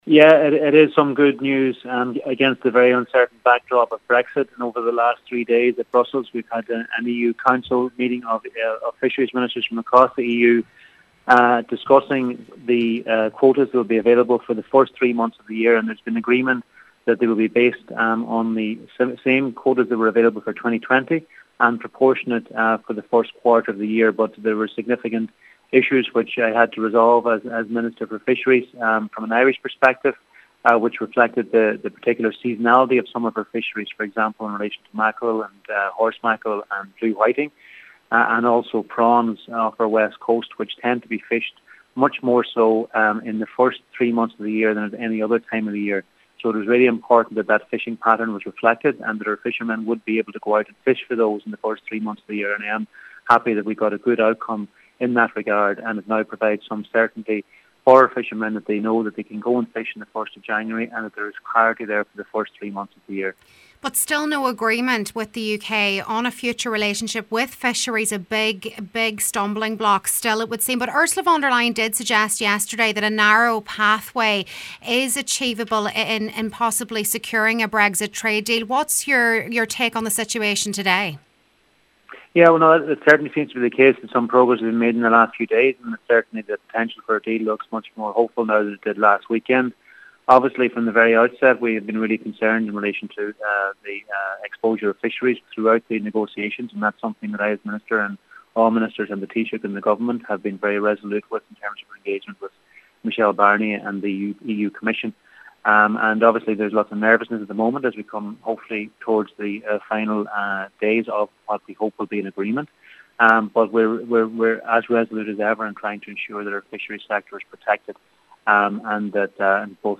Agriculture Minister Charlie McConalogue says it’s an important interim measure aiming to mitigate the impact of Brexit: